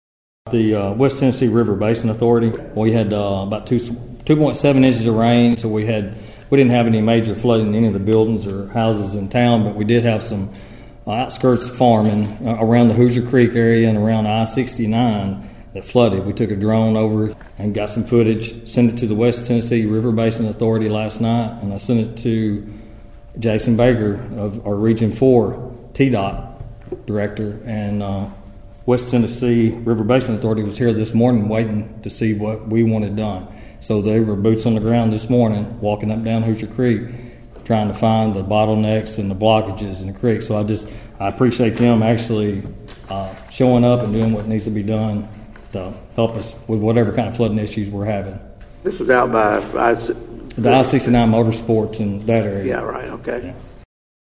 During Tuesday’s City Council meeting, McTurner told board members about work being done to eliminate flooding in the city.(AUDIO)